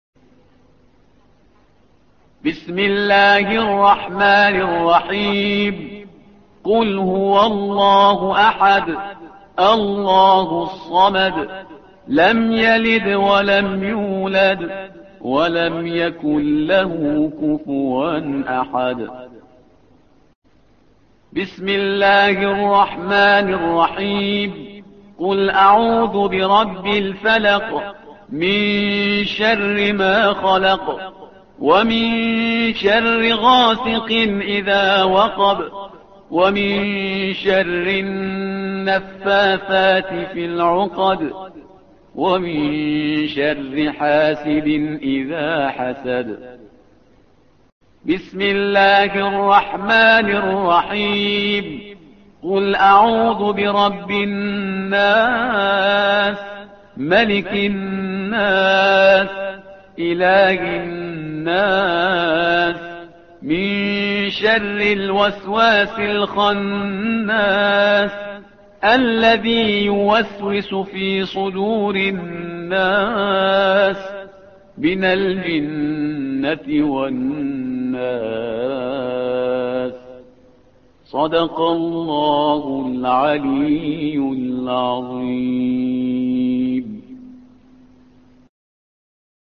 الصفحة رقم 604 / القارئ